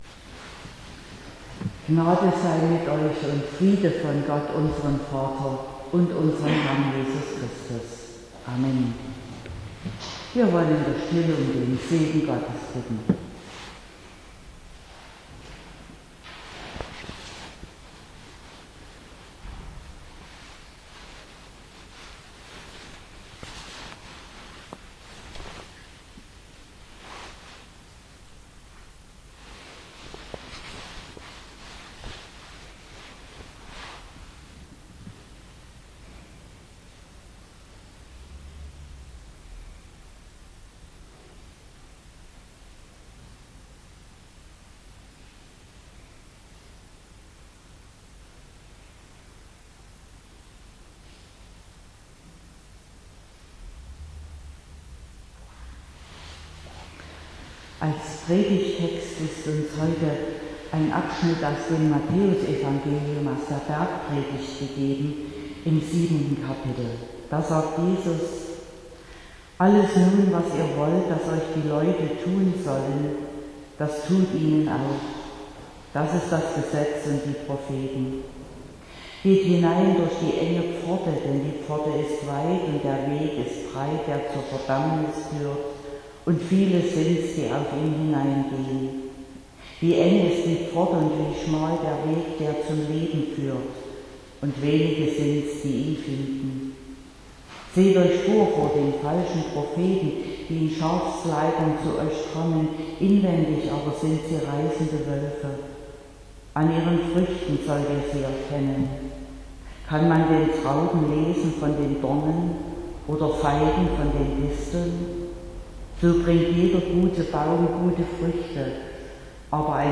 17.11.2021 – Gottesdienst
Predigt und Aufzeichnungen